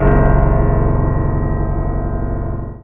55y-pno03-c#2.aif